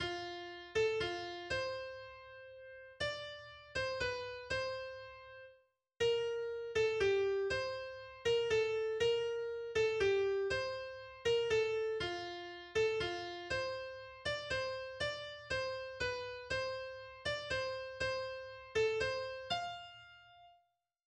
Gesungen wird es auf eine Volksmelodie aus dem Sudetenland.